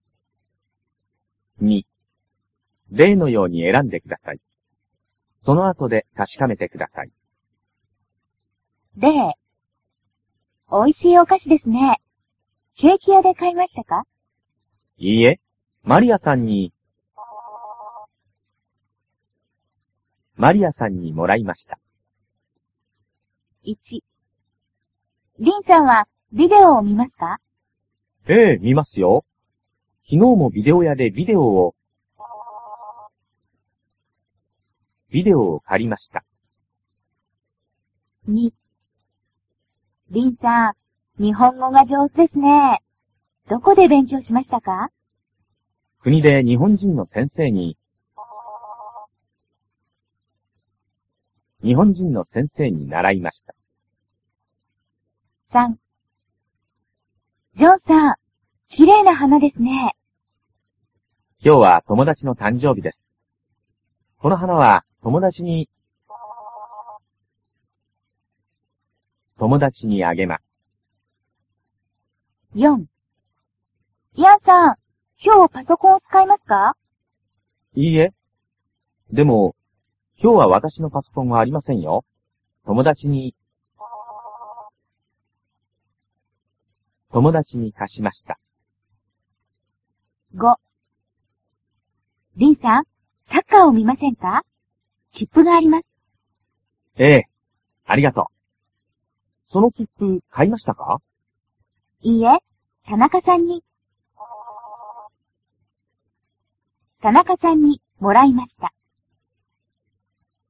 Japanese basic listening practice lesson 9